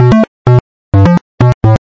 基调舞 B2 G2 128 bpm
描述：bassline dance b2 g2 128 bpm.wav
Tag: 最小 狂野 房屋 科技 配音步 贝斯 精神恍惚 舞蹈 俱乐部